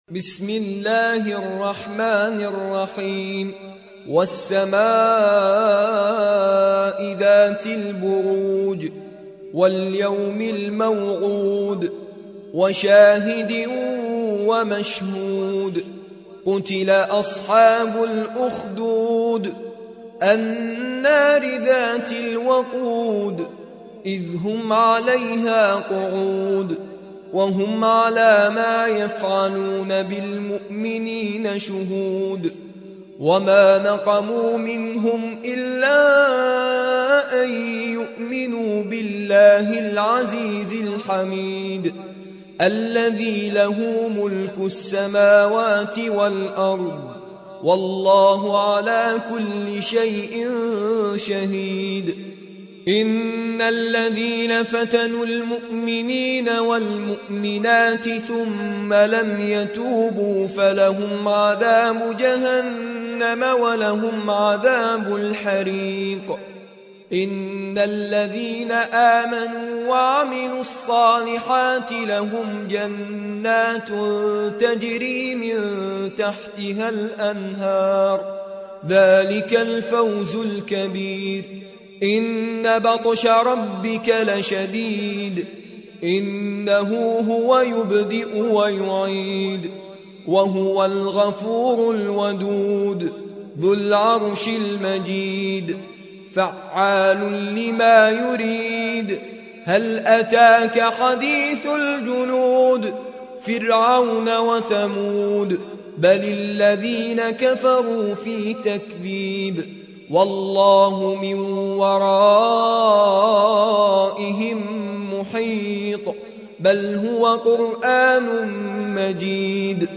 85. سورة البروج / القارئ